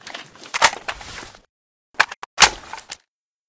rpk74_reload.ogg